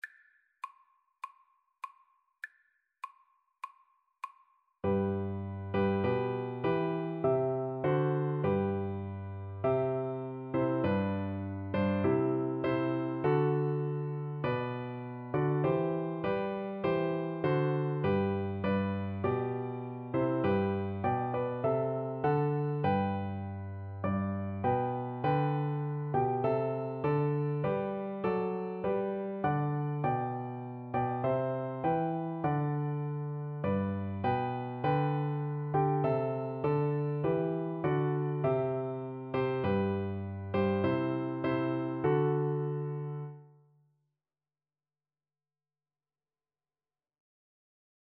Play (or use space bar on your keyboard) Pause Music Playalong - Piano Accompaniment Playalong Band Accompaniment not yet available transpose reset tempo print settings full screen
4/4 (View more 4/4 Music)
G major (Sounding Pitch) (View more G major Music for Cello )
Traditional (View more Traditional Cello Music)